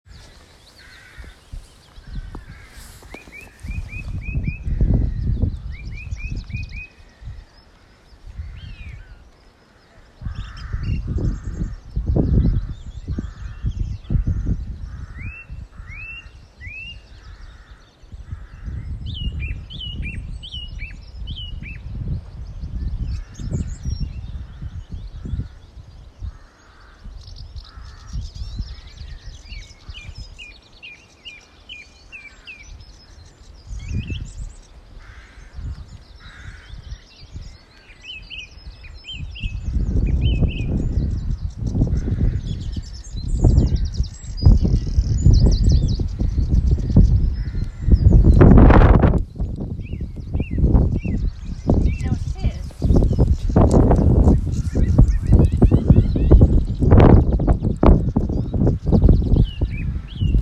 Right out the gate on the West Highland Way.
Anyway, at the start gate, a chorus of birds let loose their lungs and kept on singing for the whole walk.
If GLORIOUS has a sound, I’ve heard it.